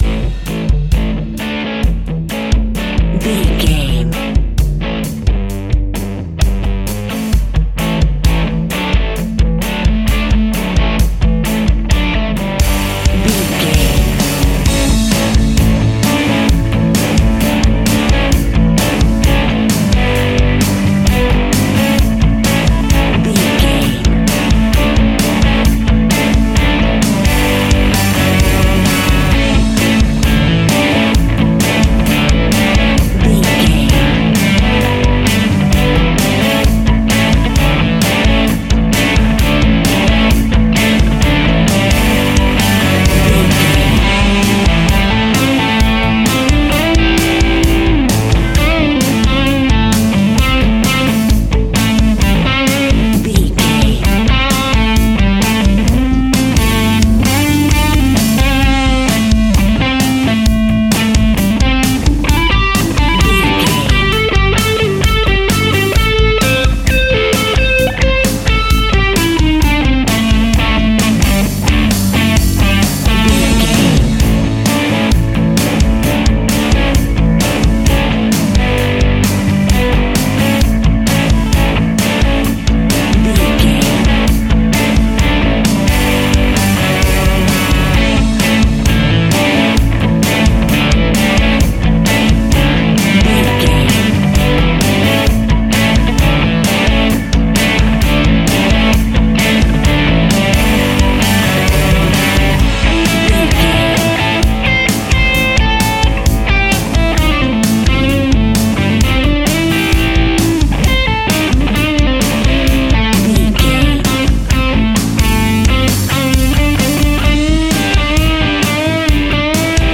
Ionian/Major
energetic
driving
aggressive
electric guitar
bass guitar
drums
hard rock
heavy metal
blues rock
distortion
instrumentals
heavy drums
distorted guitars
hammond organ